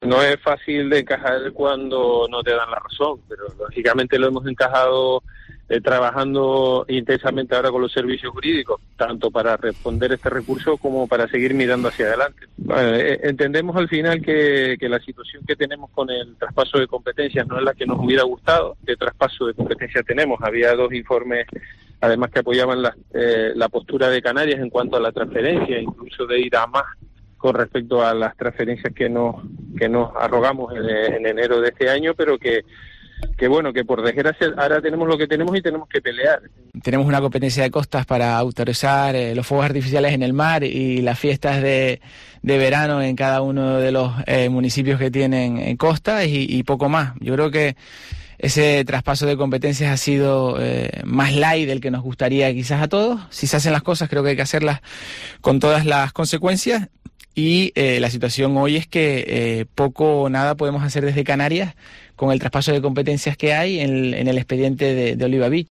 En nuestros micrófonos, hemos tratado este asunto con los responsables de costas en el archipiélago canario.
Tony Acosta, director general de Costas y Mariano Hernández, consejero Transición Ecológica